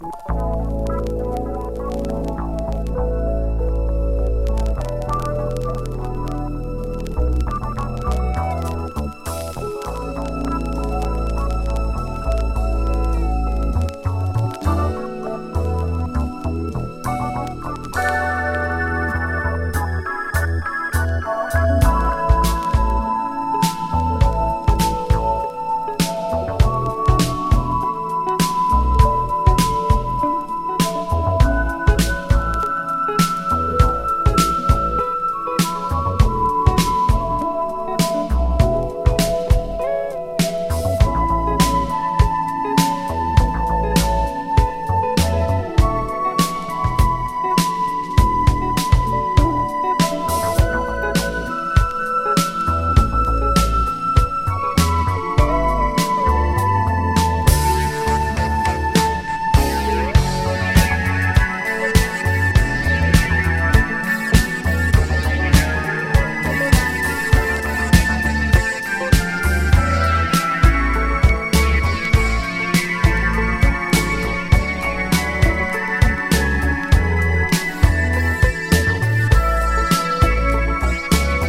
スペースサイザー使用メロウ・エレクトリカル・フュージョン